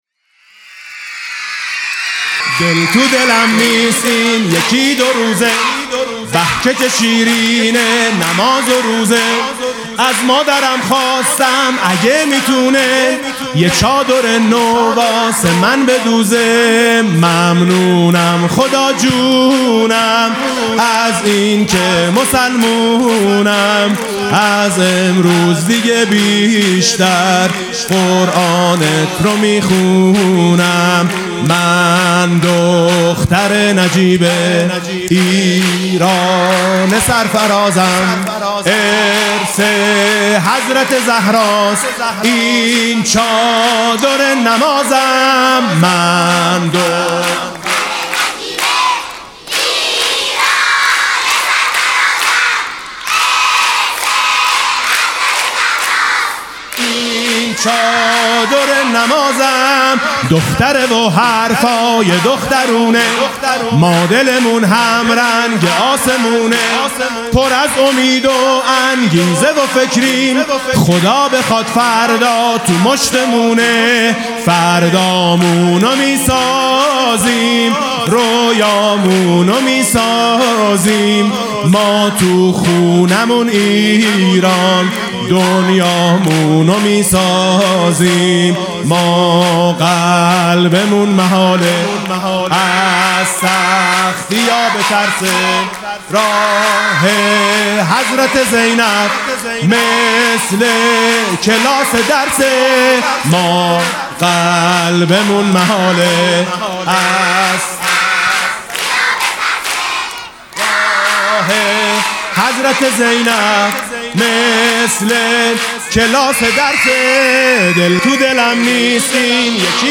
عنوان: مجموعه مداحی ویژه کودکان و نوجوانان